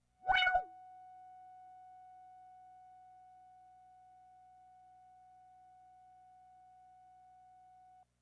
描述：通过Modular Sample从模拟合成器采样的单音。
Tag: F6 MIDI音符-90 Sequntial-MAX 合成器 单票据 多重采样